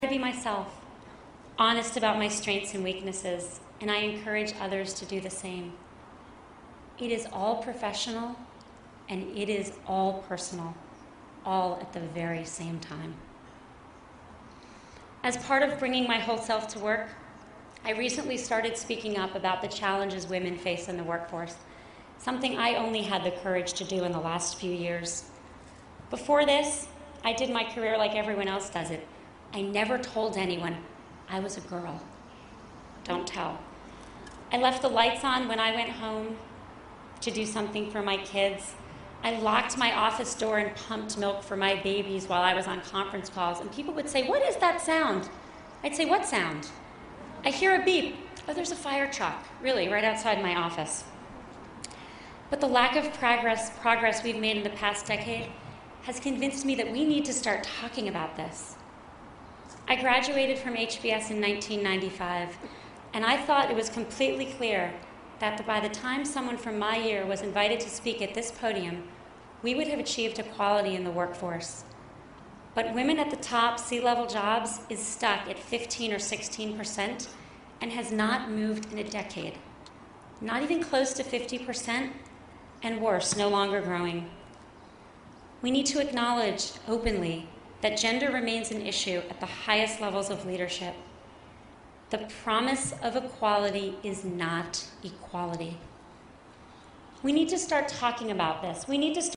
公众人物毕业演讲第186期:桑德伯格2012哈佛商学院(11) 听力文件下载—在线英语听力室